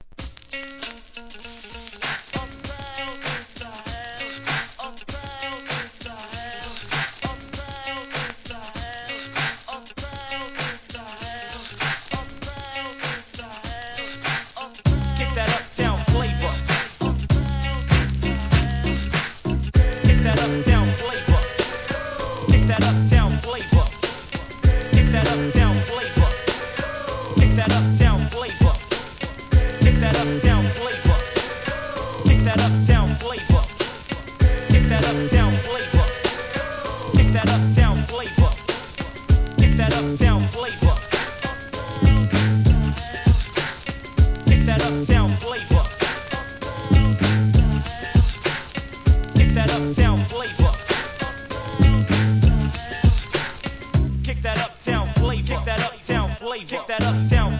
12inch
HIP HOP/R&B